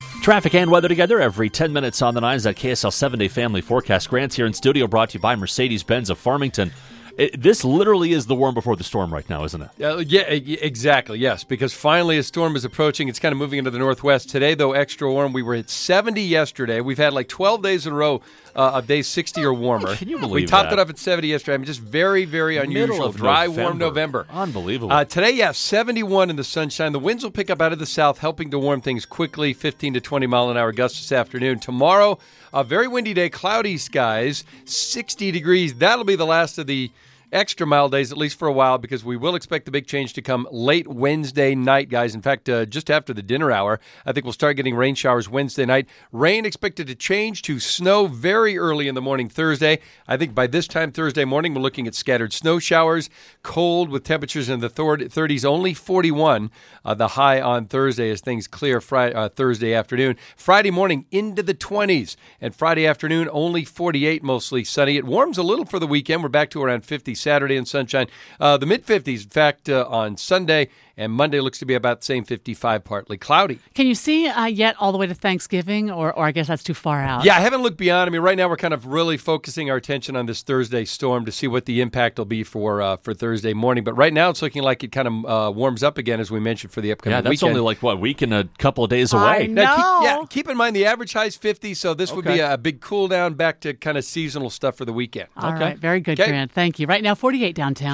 7-Day Forecast